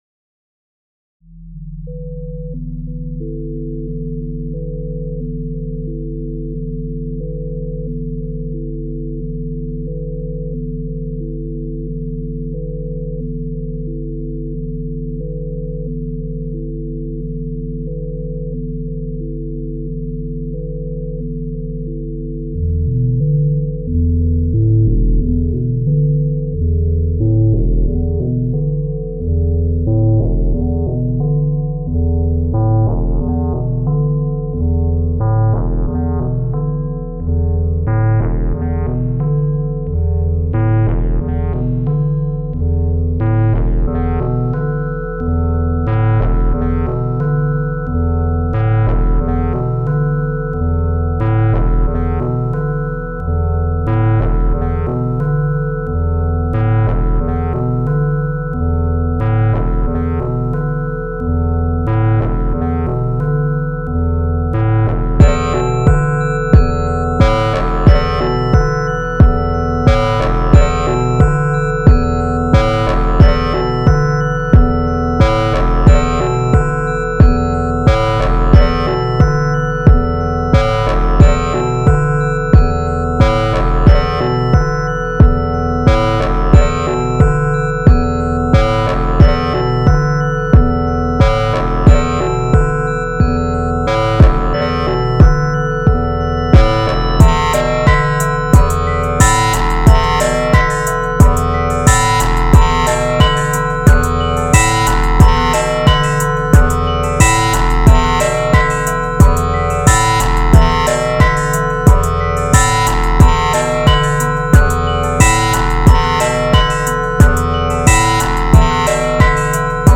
Epic & Minimalish